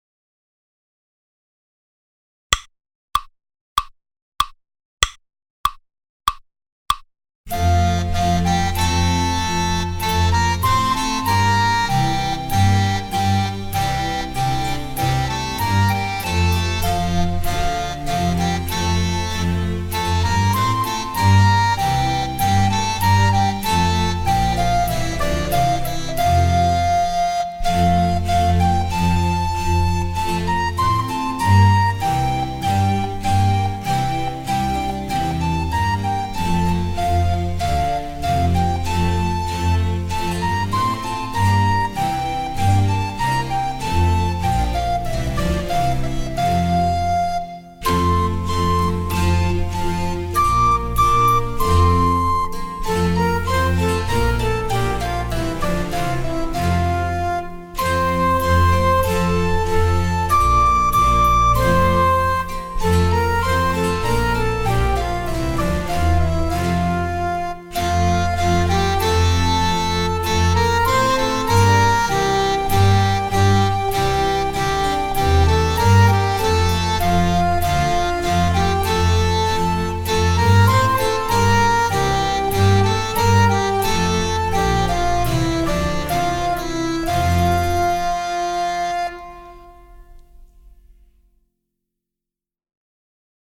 Proposta C: Audio velocidade lenta con frauta
Susato_LENTO_frauta.mp3